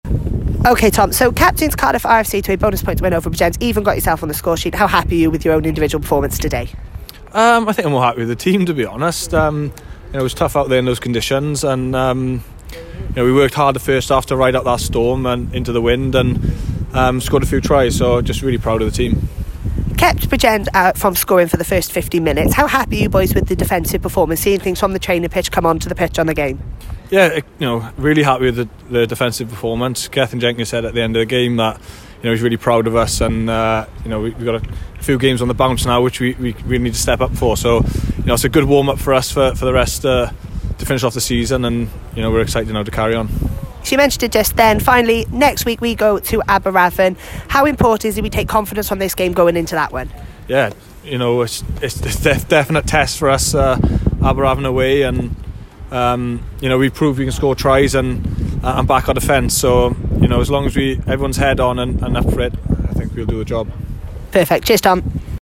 Post-Match Interviews.